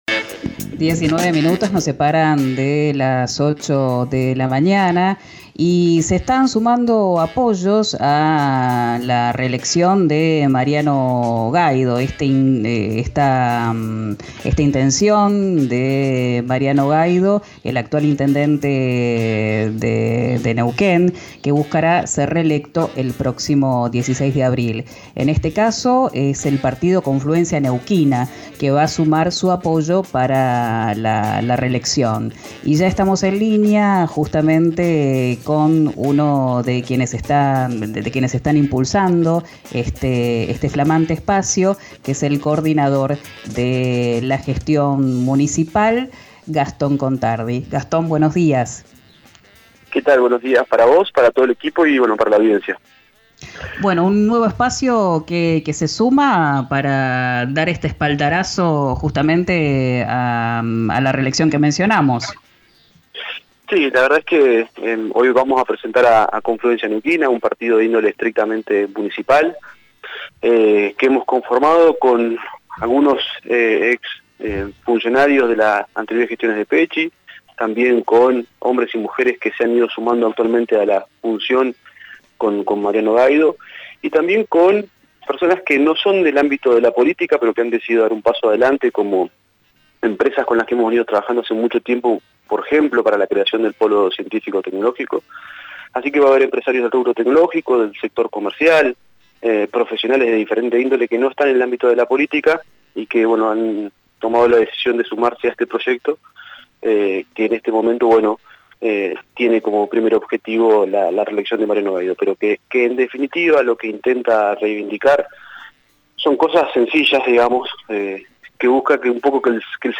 El funcionario de primera línea del jefe comunal habló hoy con «Quien dijo verano» por RÍO NEGRO RADIO. En la entrevista no dijo cómo estará integrada la lista de concejales y concejalas, que irá liderada por el jefe comunal; sin embargo, adelantó que parte de los presentes en la conferencia, serán parte de la lista de concejales.
Escuchá a Gastón Contadi, coordinador de Gestión Municipal de la ciudad de Neuquén, del partido Confluencia, en “Quién dijo verano”, por RÍO NEGRO RADIO: